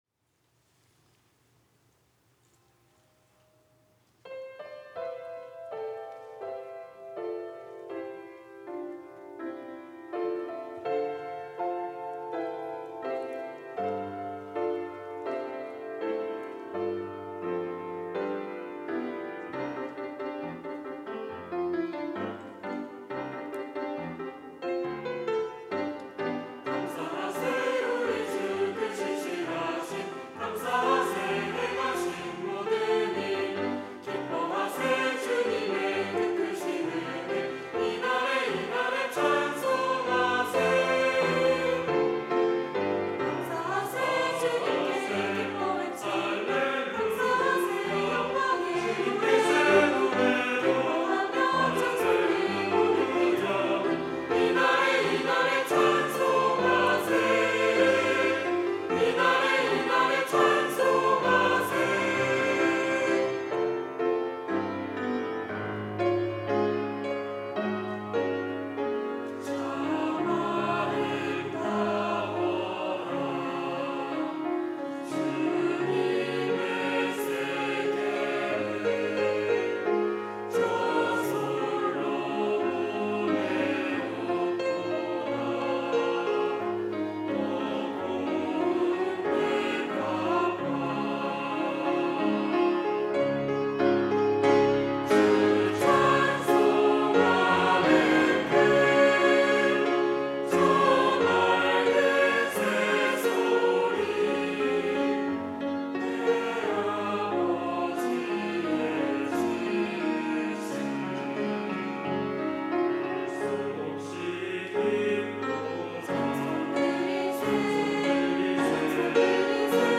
특송과 특주 - 이 날에 감사하세
청년부 카이노스 찬양대